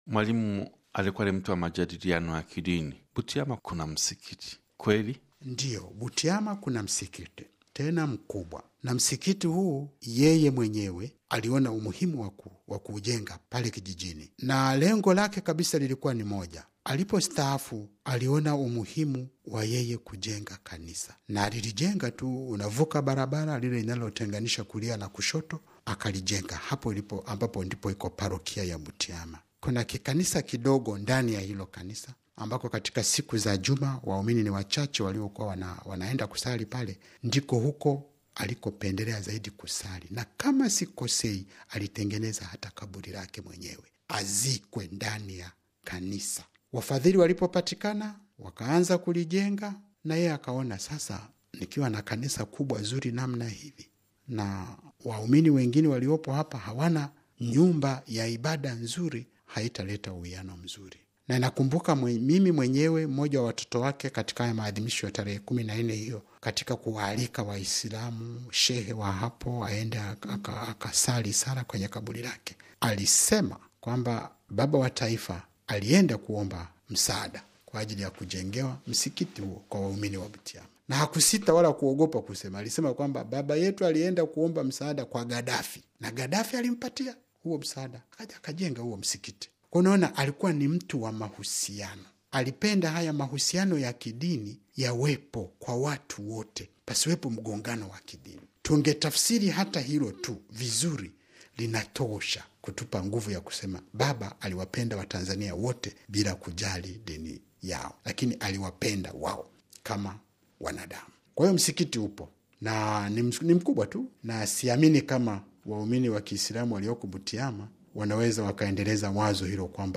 Katika mahojiano maalum na Radio Vatican, Askofu Michael Msonganzila wa Jimbo Katoliki Musoma, Tanzania anasema kwamba, Mwalimu Nyerere baada ya kung’atuka kutoka madarakani, alitamani kujenga Kanisa ili aweze kupata mahali pa kusali, karibu na nyumbani kwake Kijijini Butiama.